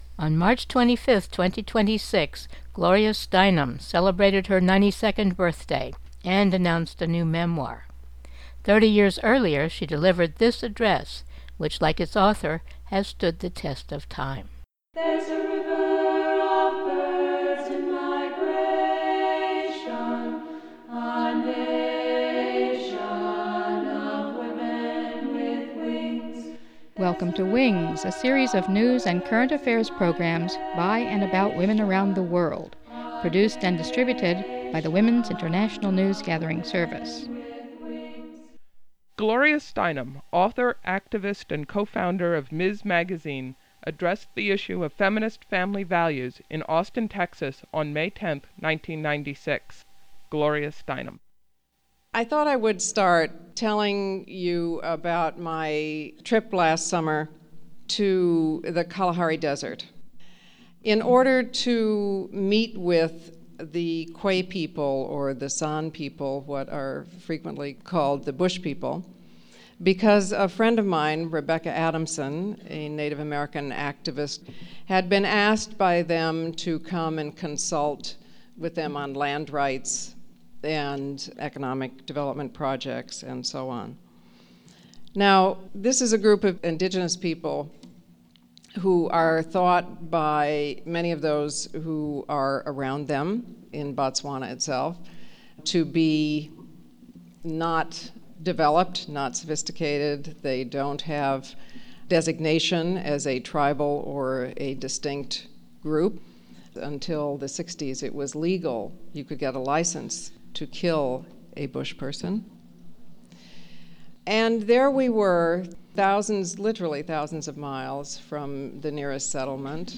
#02-26 Gloria Steinem 1996 … Speech on Feminist Family Values – WINGS: Women’s International News Gathering Service
Thirty years ago, she addressed the Feminist Family Values forum hosted by the Foundation for a Compassionate Society, in Austin, Texas.